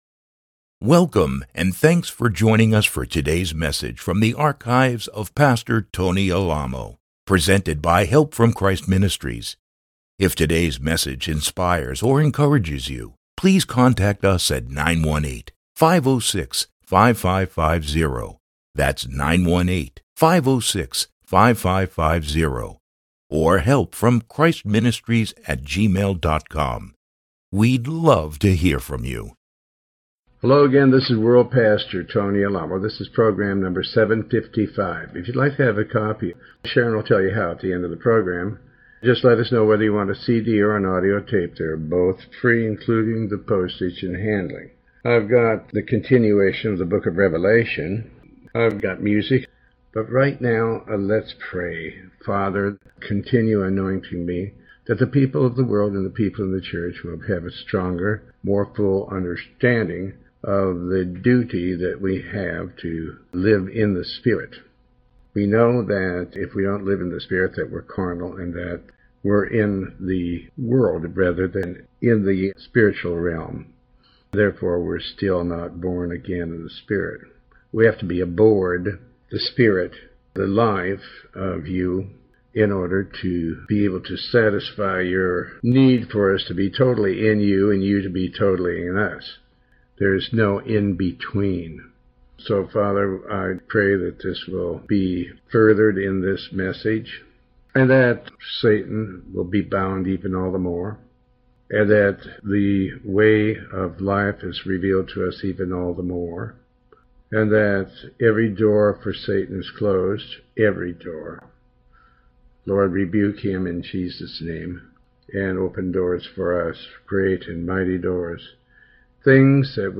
Sermon 755A